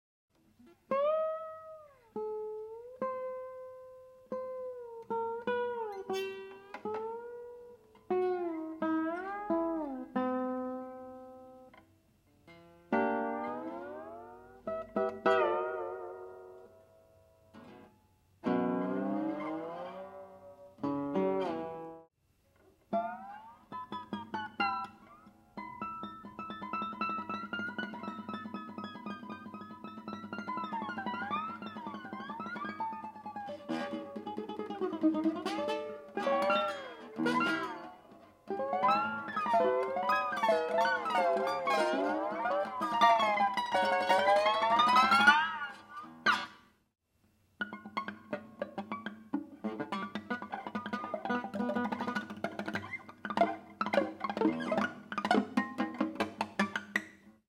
113. Slide.m4v